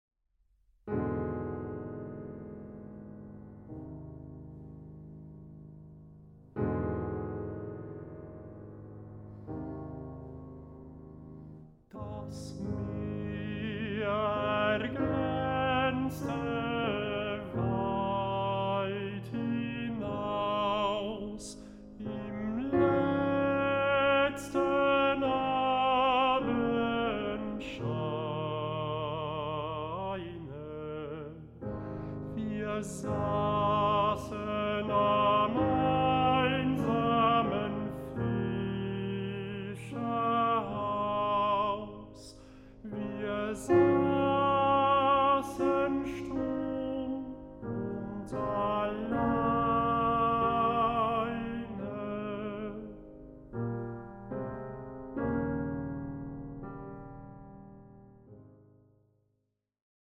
Bassbariton
Klavier
Aufnahme: Ölbergkirche Berlin, 2024